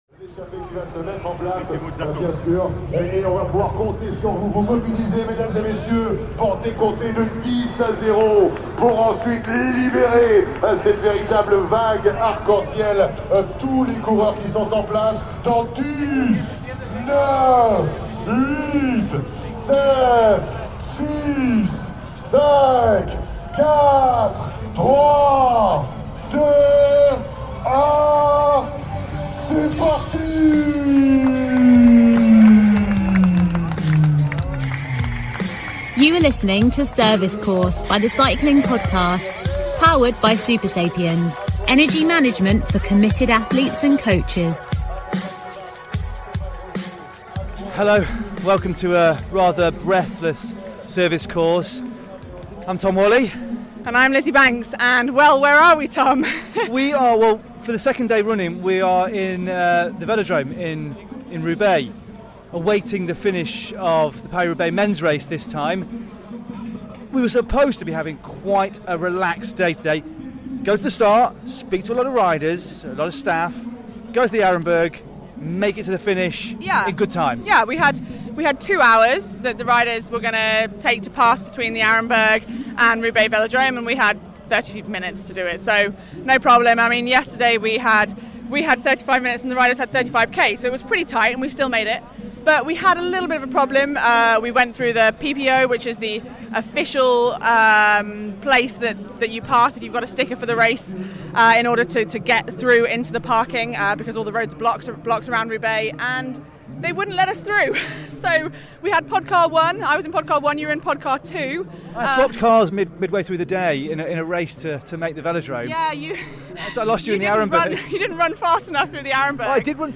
This episode of Service Course was recorded on location at the Hell of the North.